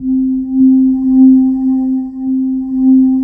20PAD 01  -L.wav